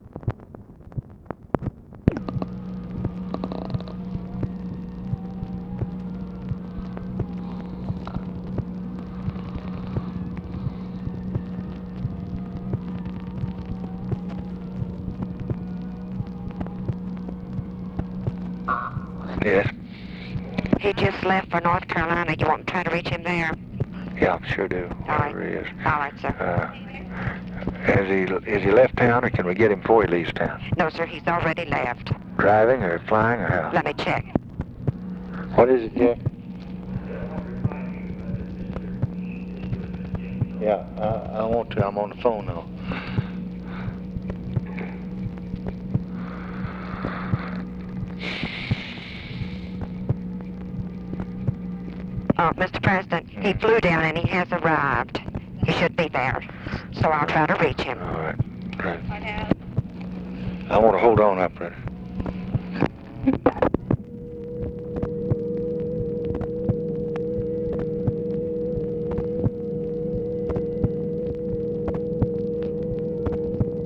Conversation with TELEPHONE OPERATOR, September 4, 1964
Secret White House Tapes